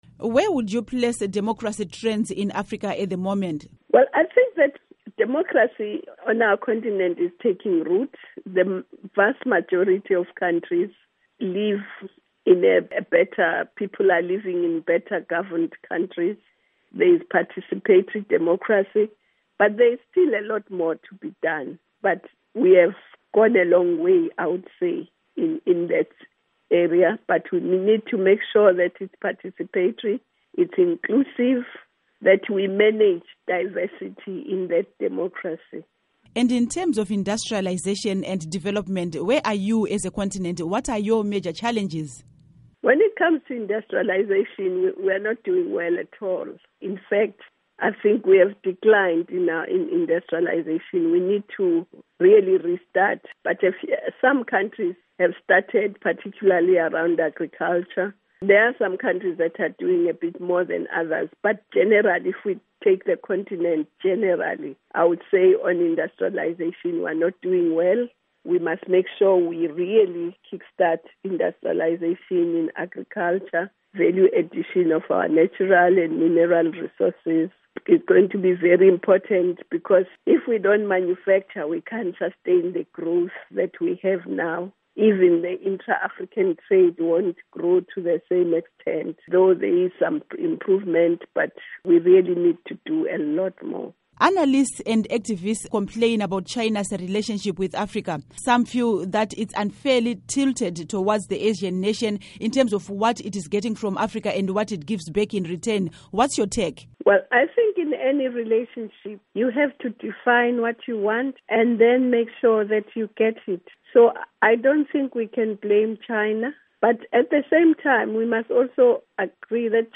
Interview With Dr. Nkosazana Dlamini-Zuma